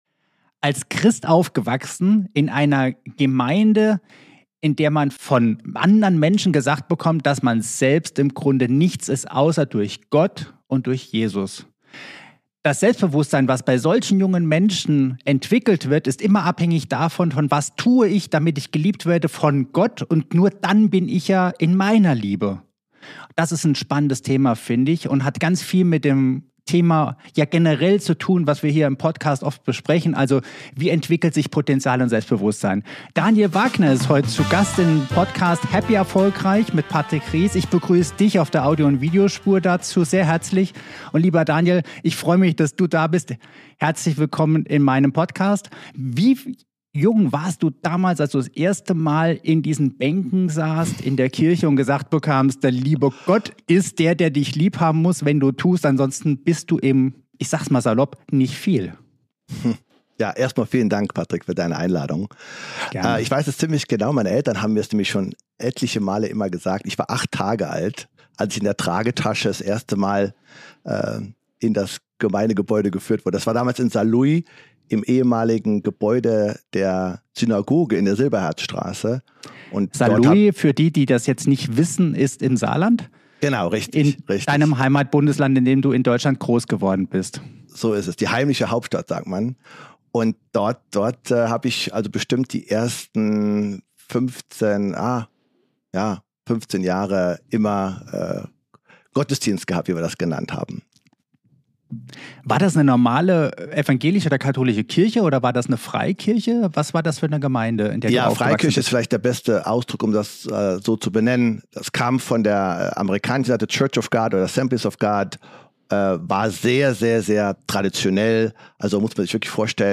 Interview ~ HappyErfolgReich